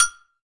9HIAGOGO.wav